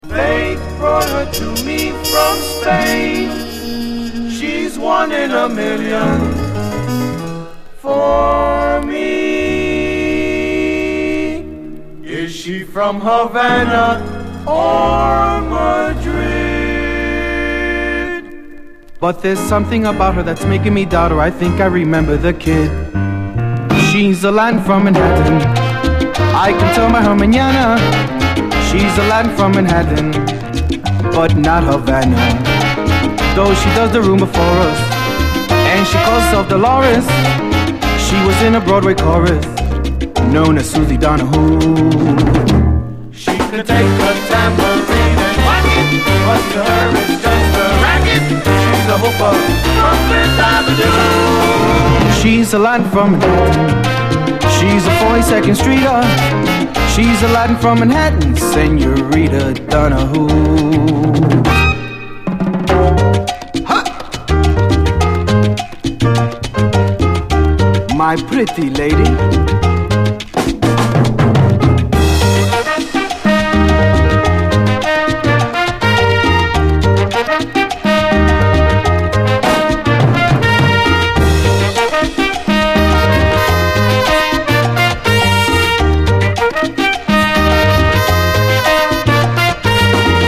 ジャケ上辺下辺テープ補修/ 傑作ニューソウル！
70’ソウル傑作アルバム！最高ドリーミー・ソウル